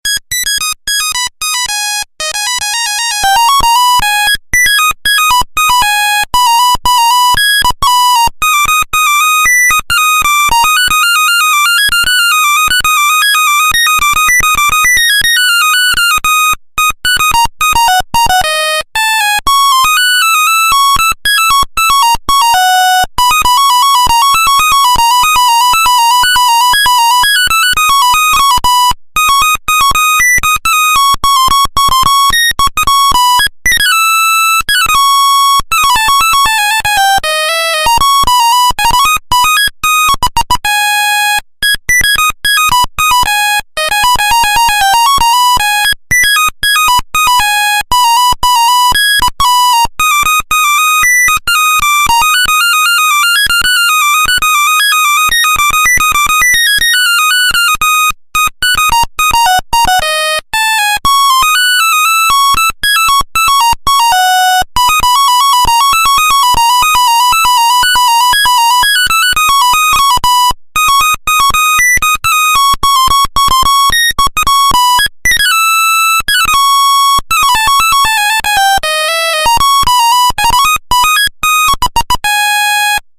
Звук Мелодии